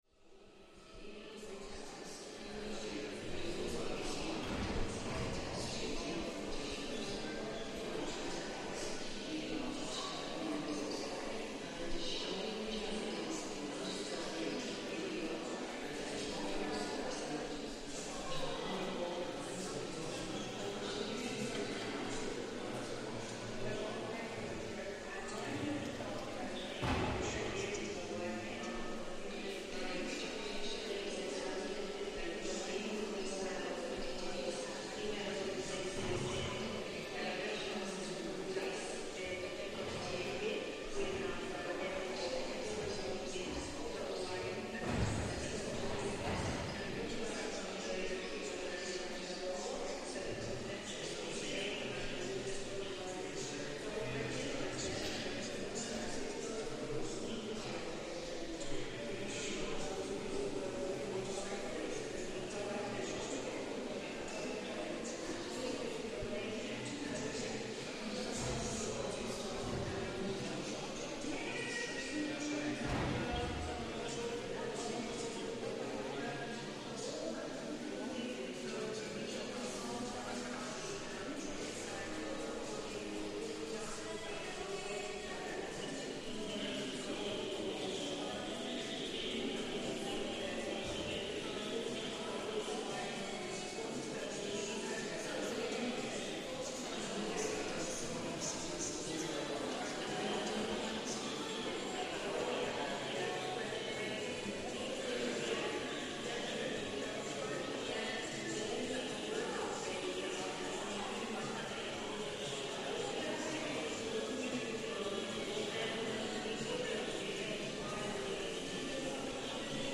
The sound of "Babel" by Cildo Meireles in the Tate Modern - the catalogue description reads: